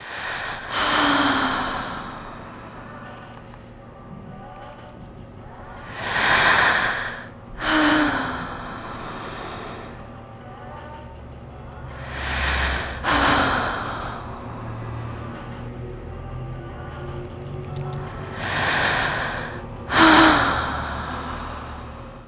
Track 07 - Female Breath FX.wav